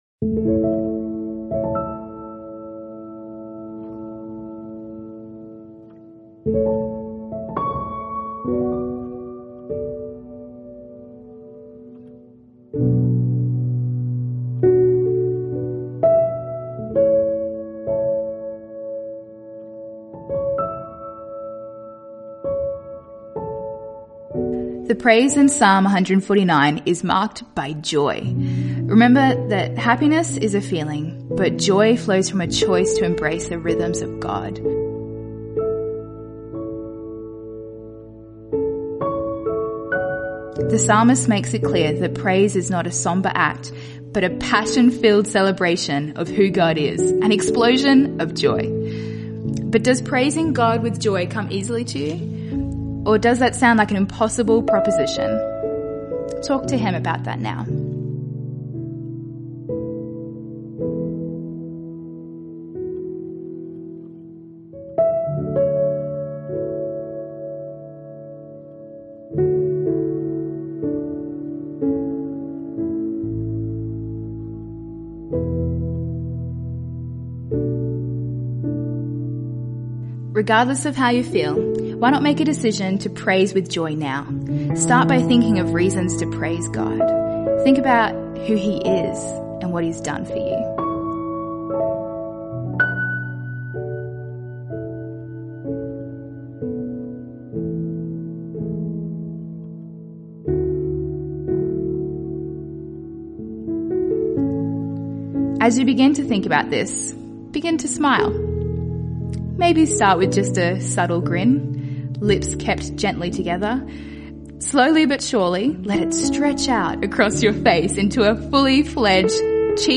After you have completed today’s reading from the Bible, we encourage you to set aside a moment to listen along to the audio guide provided as we pray and allow God to speak to us through His word.